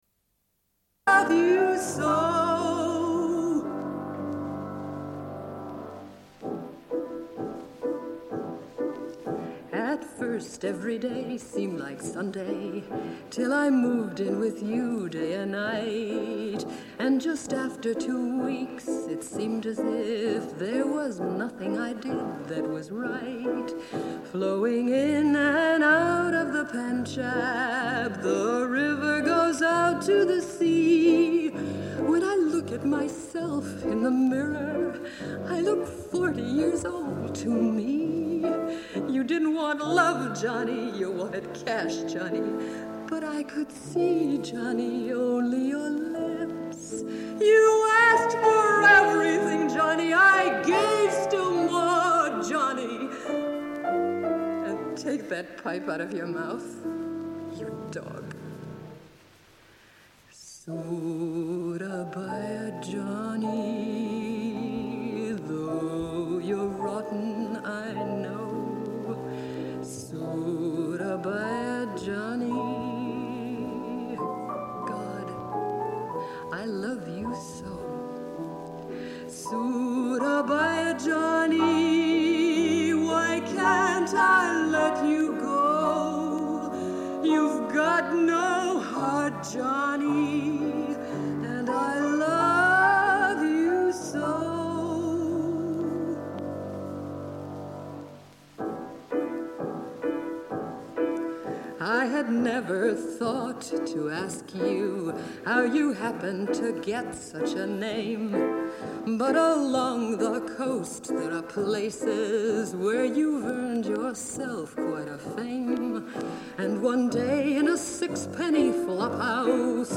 Une cassette audio, face A31:19